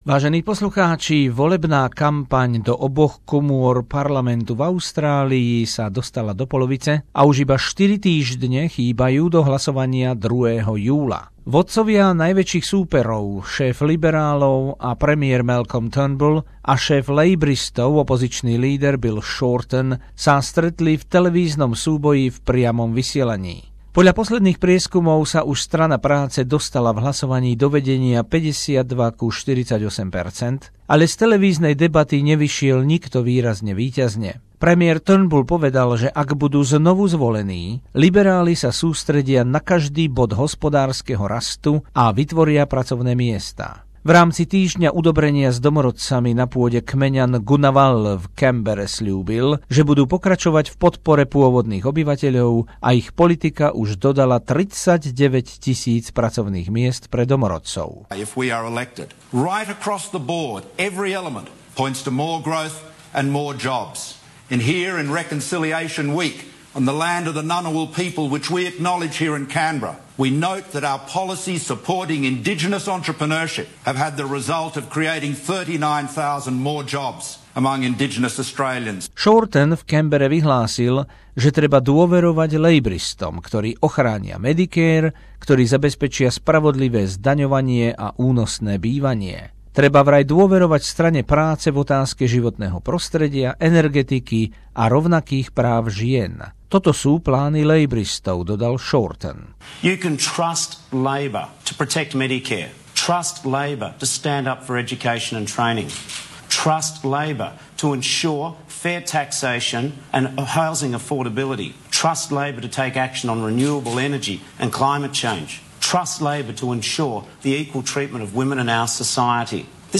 Rozšírená správa o ďalšom týždni volebnej kampane pred federálnymi voľbami 2016 z dielne spravodajcov SBS, vysielaná v piatok 3. júna